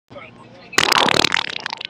Wet Fart On Buss Bouton sonore
Pranks Soundboard2 views